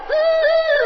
Musiky Bass Free Samples: Voz